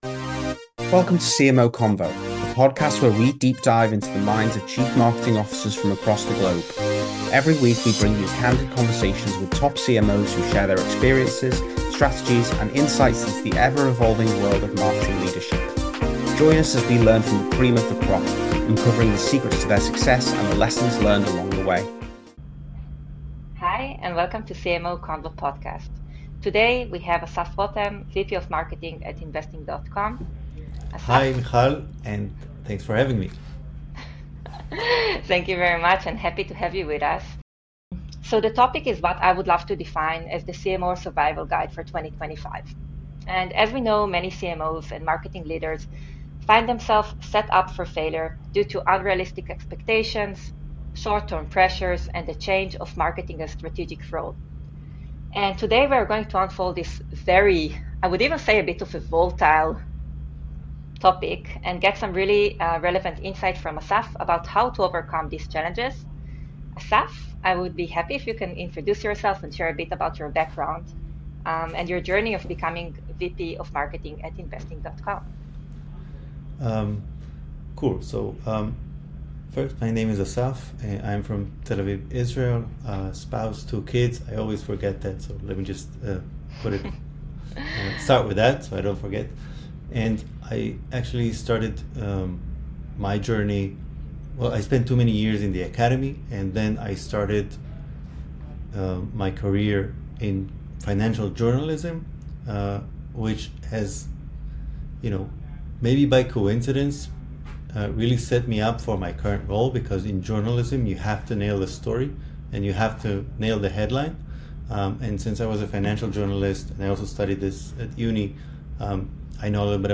In each episode, we have in-depth conversations with CMOs and top-level marketers from around the world, across every industry and level of experience, in order to get their insights into what it takes to excel at the very top of the marketing hierarchy.